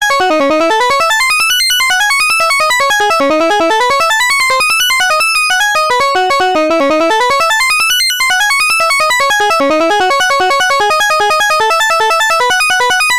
再現した音が出来ました。
それっぽい音が鳴った！！！！！！！！！